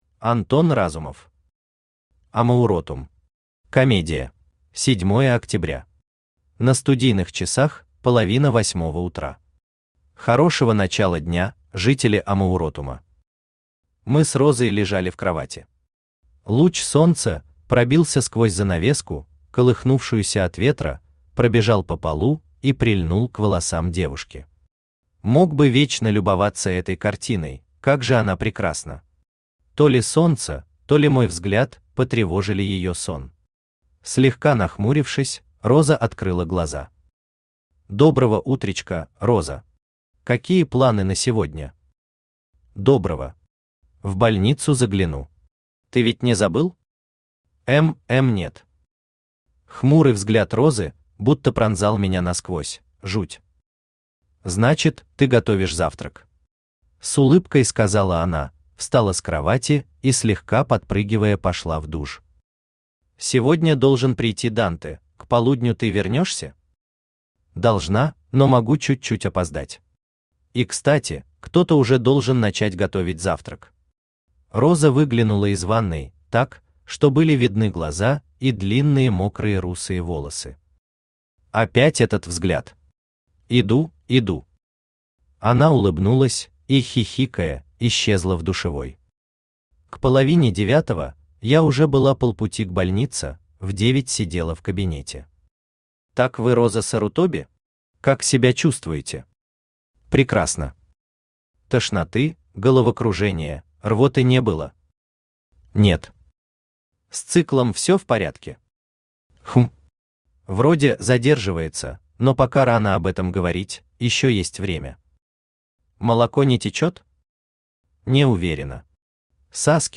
Аудиокнига Амауротум. Комедия | Библиотека аудиокниг
Комедия Автор Антон Андреевич Разумов Читает аудиокнигу Авточтец ЛитРес.